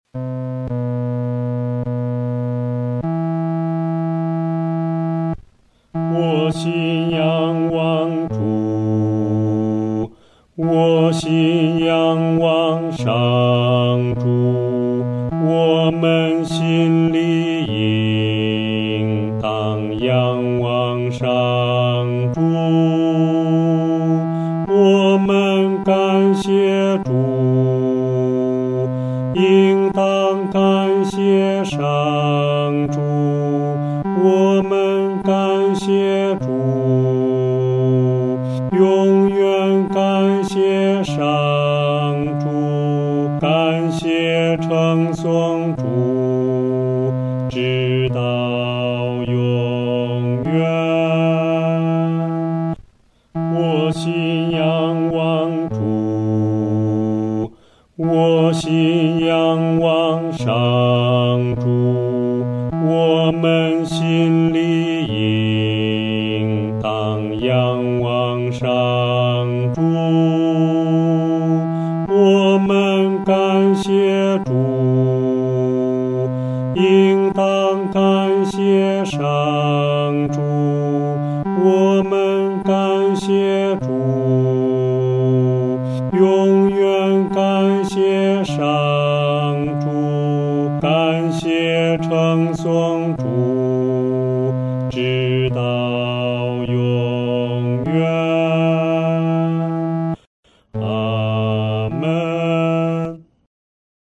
男低
本首圣诗由网上圣诗班 (石家庄二）录制